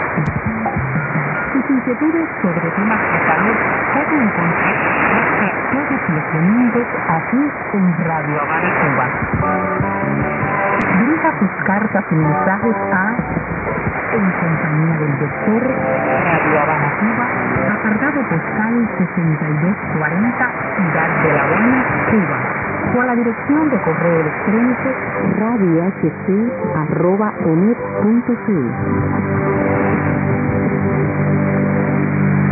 ID: identification announcement